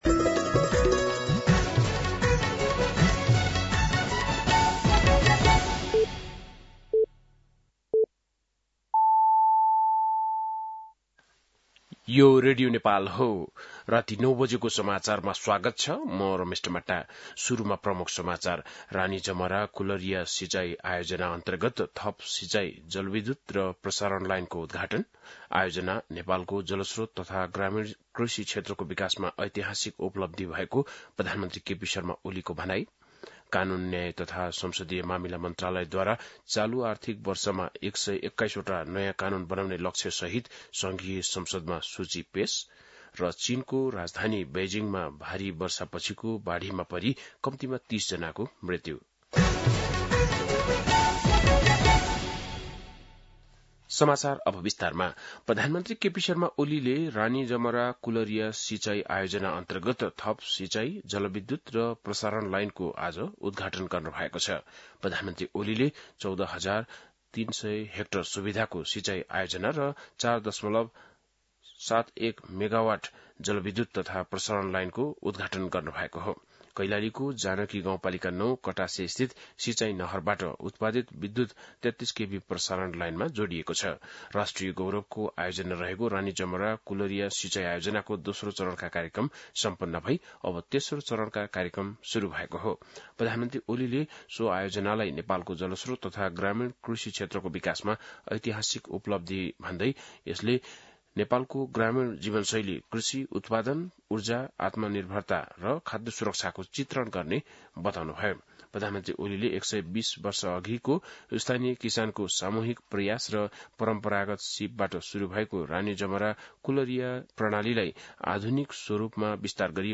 बेलुकी ९ बजेको नेपाली समाचार : १३ साउन , २०८२